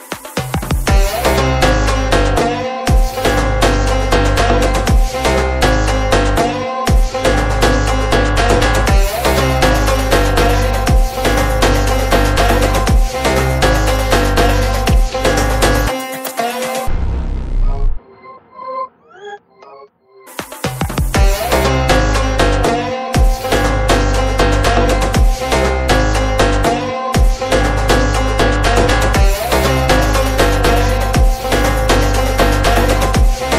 powerful and stylish background music